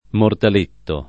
mortaletto [ mortal % tto ]